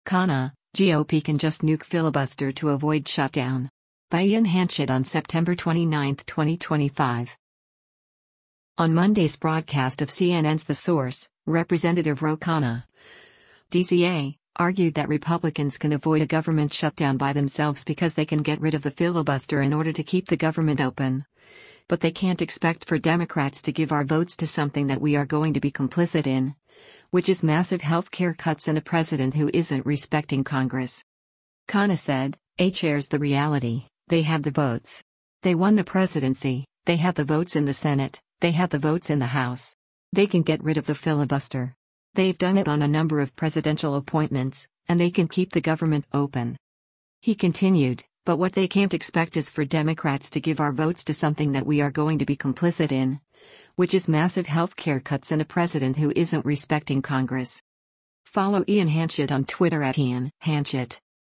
On Monday’s broadcast of CNN’s “The Source,” Rep. Ro Khanna (D-CA) argued that Republicans can avoid a government shutdown by themselves because “They can get rid of the filibuster” in order to keep the government open, but they can’t expect “for Democrats to give our votes to something that we are going to be complicit in, which is massive healthcare cuts and a president who isn’t respecting Congress.”